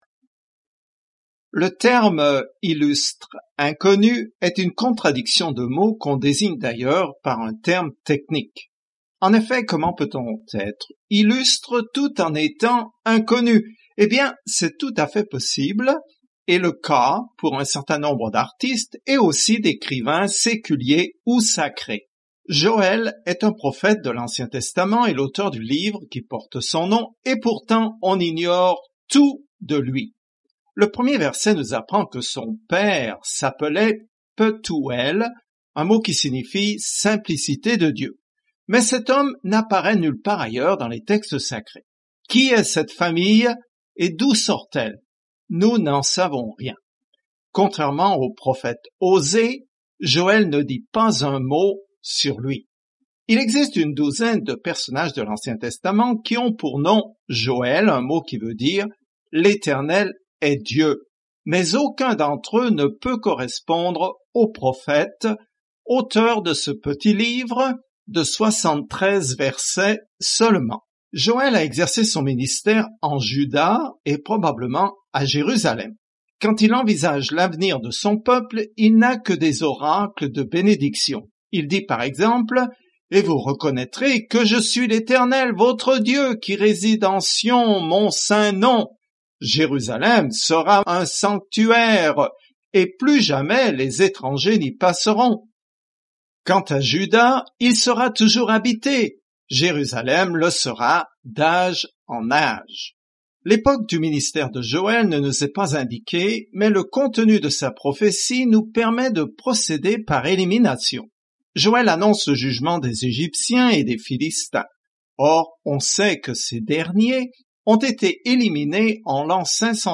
Écritures Joël 1 Commencer ce plan Jour 2 À propos de ce plan Dieu envoie une invasion de sauterelles pour juger Israël, mais derrière son jugement se cache la description d’un futur « jour du Seigneur » prophétique où Dieu aura enfin son mot à dire. Parcourez quotidiennement Joël en écoutant l’étude audio et en lisant certains versets de la parole de Dieu.